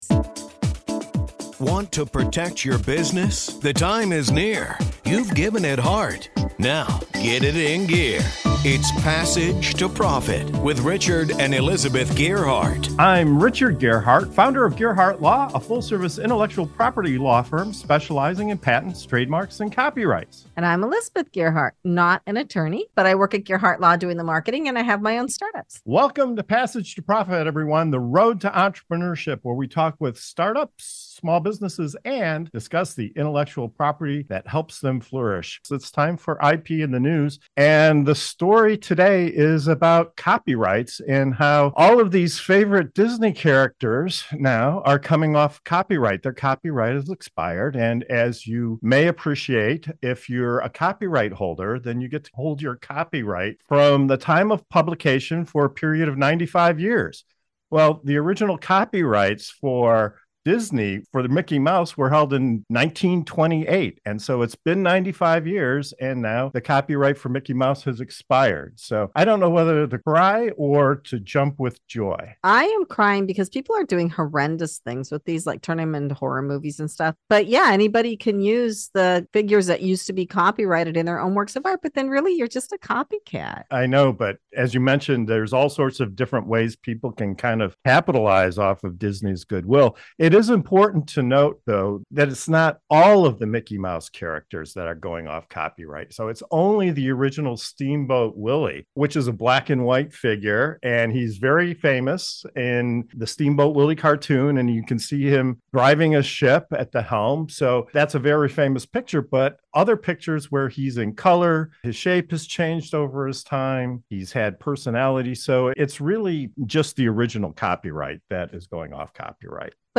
From the specter of horror movie adaptations to concerns about creative dilution, the episode navigates the fine line between artistic freedom and the protection of intellectual property. Join the discussion as the hosts share insights from legal perspectives, artistic fears, and the broader societal impact of characters like Mickey Mouse shedding their copyright shackles.